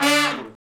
Index of /90_sSampleCDs/Roland LCDP06 Brass Sections/BRS_Section FX/BRS_R&R Falls